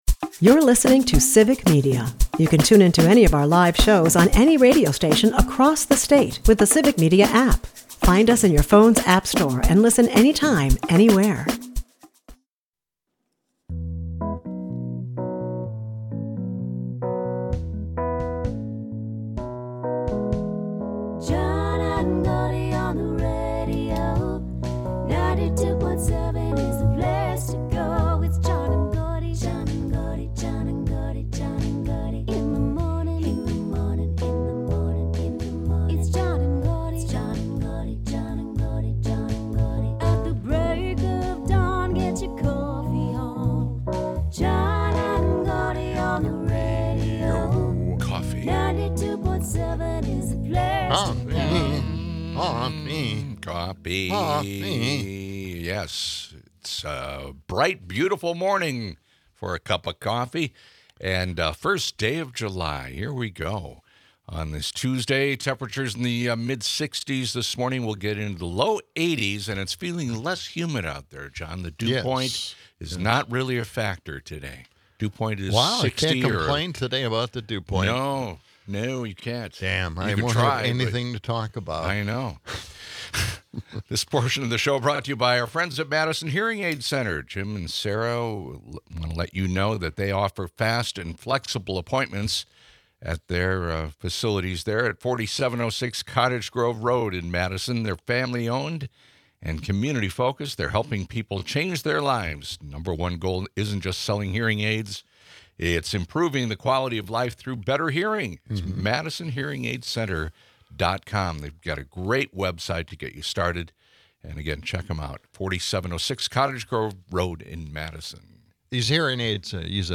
weather was at a standstill but tankini summer wasn't. All three guys in the studio were confused on what a tankini bikini was and why it was used!